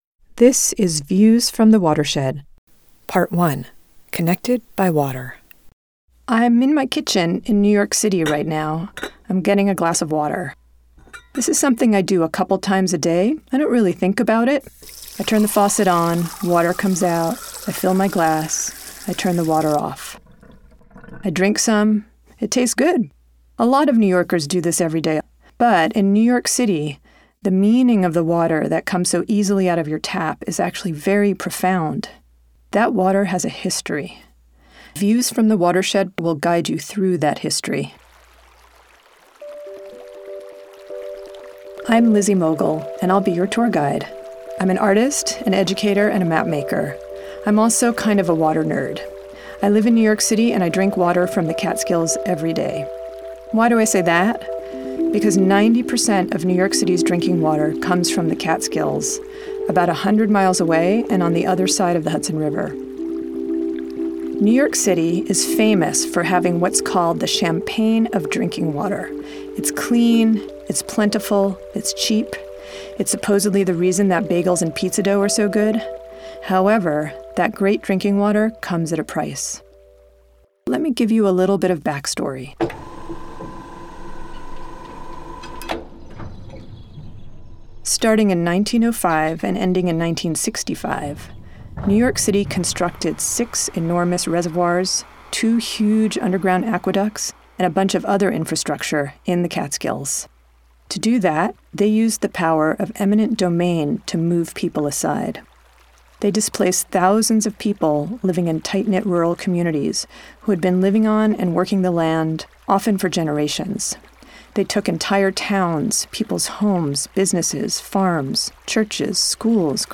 "Views from the Watershed" is about the landscape, history, and politics of New York City’s water supply, and the complicated relationship between the City and the Catskills communities that steward 90% of its water. It tells the stories of the watershed through firsthand, intimate perspectives from local people (including a historian, a dairy farmer, a former DEP commissioner, a grave restorer, and a forester) on what it means to be a part of the water system.